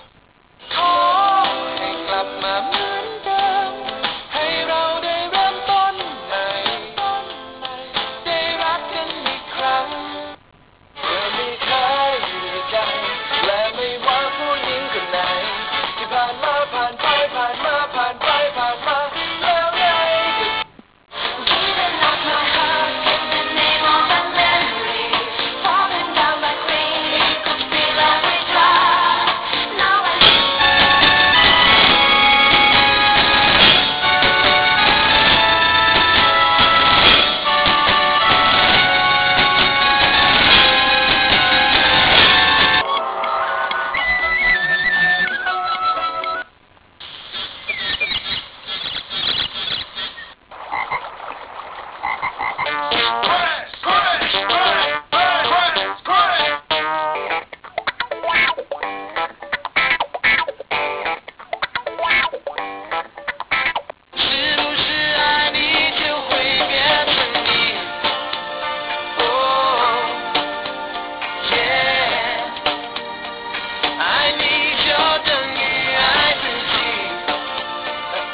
ตัวอย่างเสียง Truetone จาก Zeason M-TWO (กด Play เพื่อเริ่ม)
*ไฟล์เสียงผ่านการบีบอัด เสียงจากเครื่องจริงจะใสกว่าประมาณ 20%